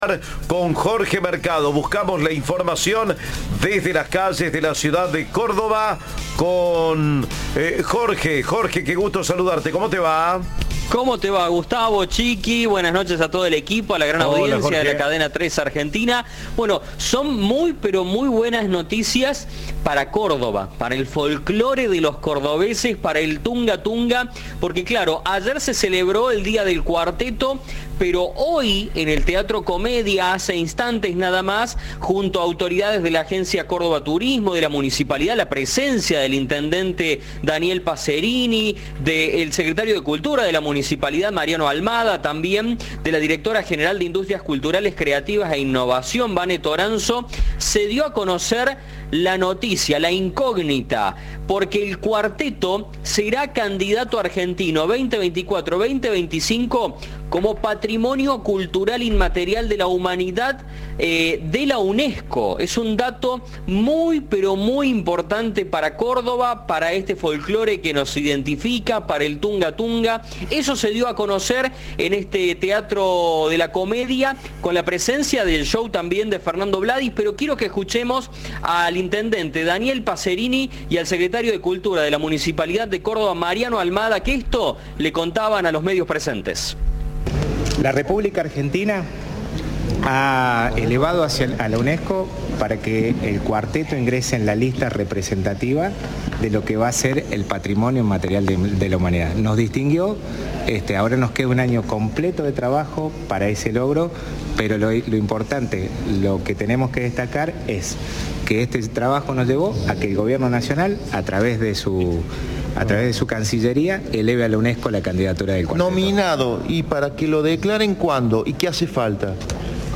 El anuncio se realizó este miércoles en el Teatro Comedia de Córdoba con la presencia del intendente, Daniel Passerini; del secretario de Cultura de la Municipalidad, Mariano Almada, y representantes de la Agencia Córdoba Turismo y de las industrias culturales.
En diálogo con Cadena 3, Passerini expresó: "La República Argentina elevó a la UNESCO que el cuarteto ingrese en la lista representativa de lo que va a ser el Patrimonio Inmaterial de la Humanidad. Ahora nos queda un año completo de trabajo para ese logro".